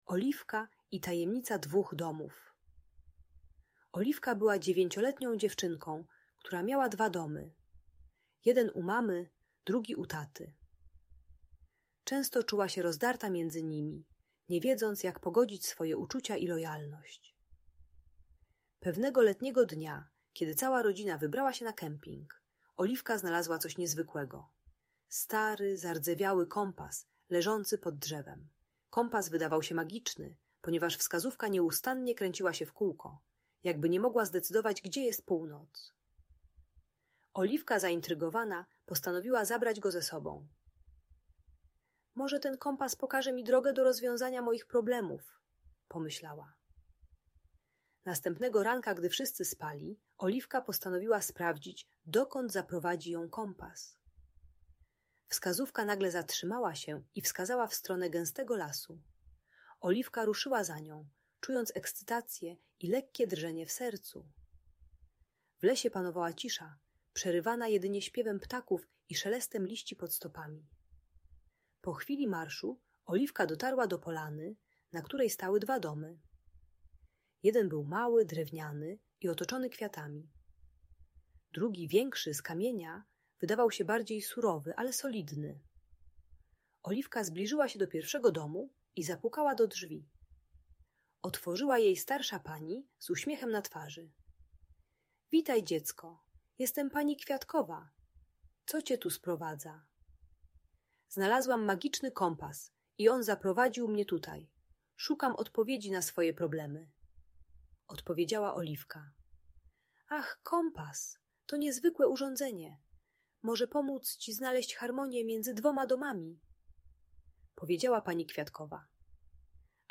Audiobook dla dzieci o rozwodzie rodziców - bajka dla dziecka które ma dwa domy po rozstaniu rodziców.